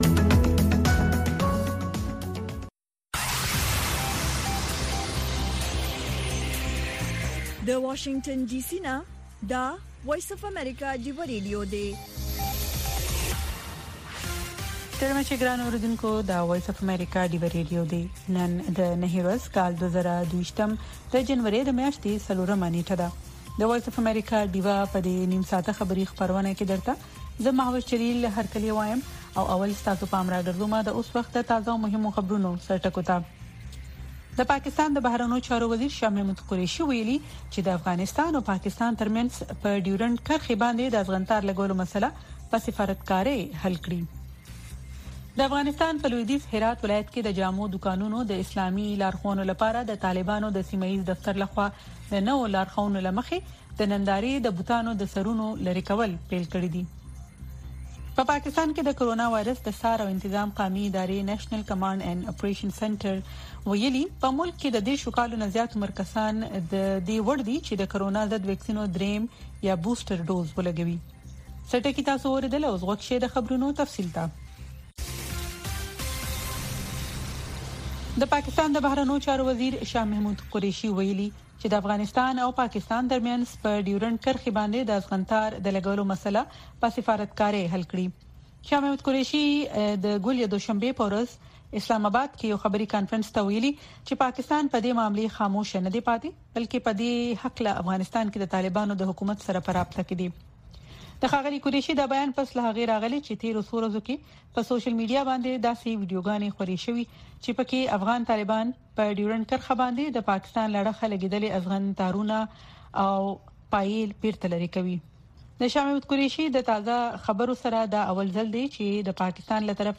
د وی او اې ډيوه راډيو سهرنې خبرونه چالان کړئ اؤ د ورځې دمهمو تازه خبرونو سرليکونه واورئ.